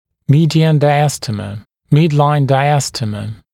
[‘miːdɪən ˌdaɪə’stiːmə] [‘mɪdlaɪn ˌdaɪə’stiːmə][‘ми:диэн ˌдайэ’сти:мэ] [‘мидлайн ˌдайэ’сти:мэ]срединная (медиальная) диастема